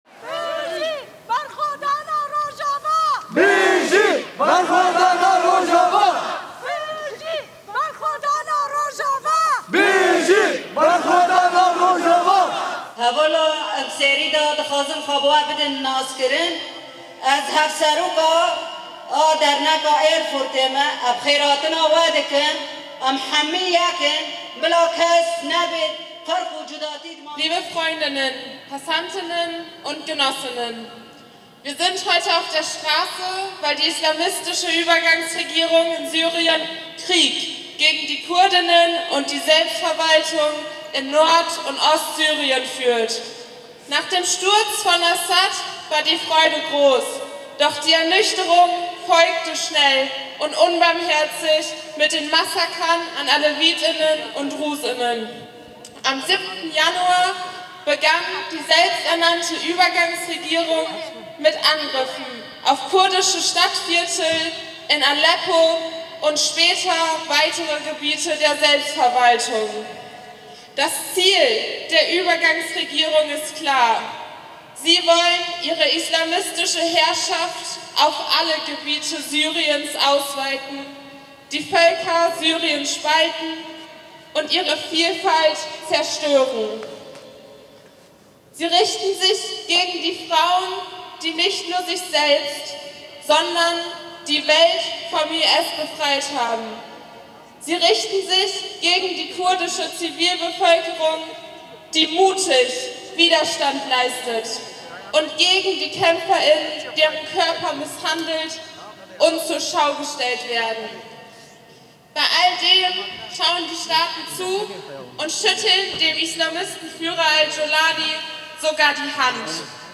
Kurdische Community ruft zu Solidarität mit Rojava auf: Eindrücke von der Demonstration am 24. Januar 2026 in Erfurt
Wir hören Demorufe wie „Bijî Berxwedana Rojava“ - [Es lebe der Widerstand von Rojava!“] oder „Nie wieder Faschismus! Nie wieder Krieg! Jin, Jiyan, Azadî!“ [„Frau, Leben, Freiheit“]
Dass wir mit dem Mikrofon da sind, scheint einige Demonstrierende zu überraschen: „Seid ihr von der Presse?“
Vor Ort schätzen wir um die 200 Teilnehmende, die sich bei eisigen Temperaturen vor dem Hauptbahnhof versammelt haben.